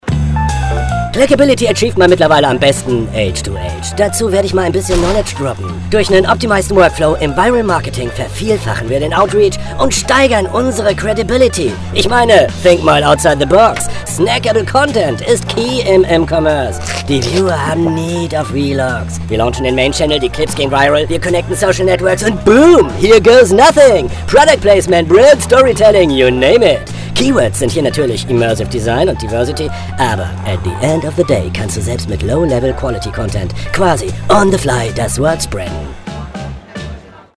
Male
Confident, Deep, Friendly, Warm
North german
Commercial Blackbeast.mp3
Microphone: Neumann TLM 102